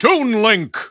The announcer saying Toon Link's name in English and Japanese releases of Super Smash Bros. Brawl.
Toon_Link_English_Announcer_SSBB.wav